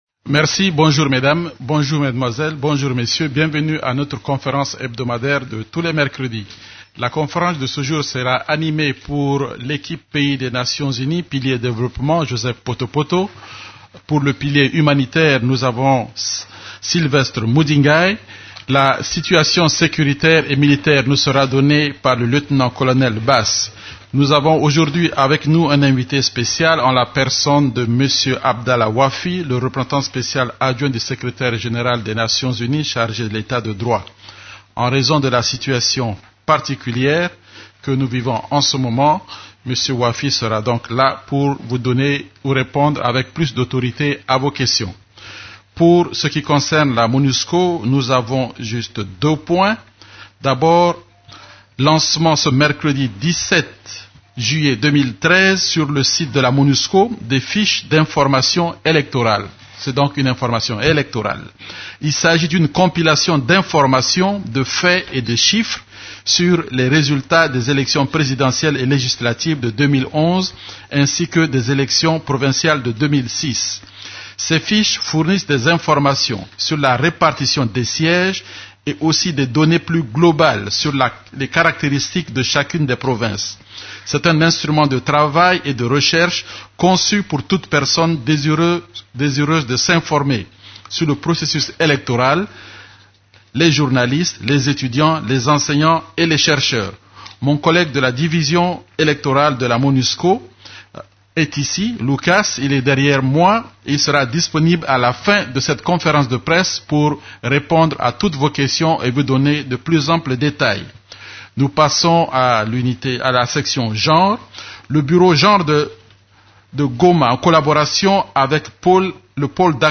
La conférence de presse hebdomadaire des Nations unies en RDC du mercredi 17 juillet a porté sur les sujets suivants: